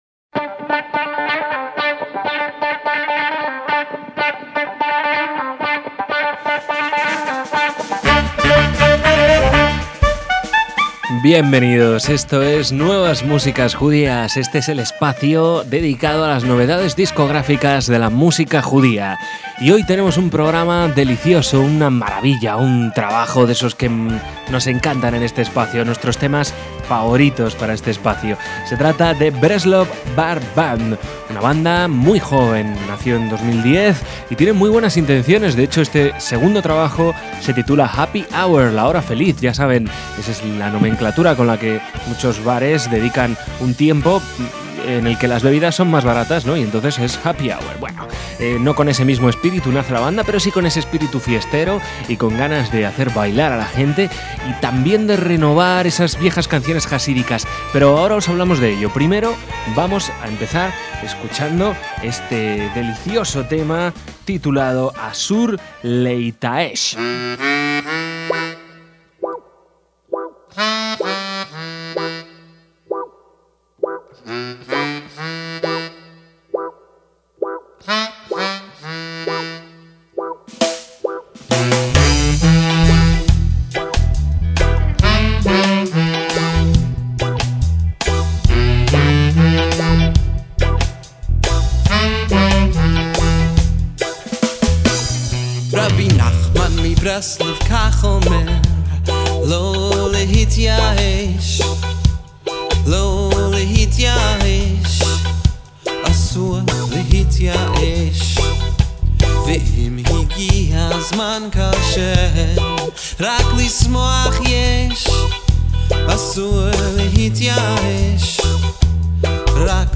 en clarinete
en saxos y flauta
a la guitarra eléctrica
en la percusión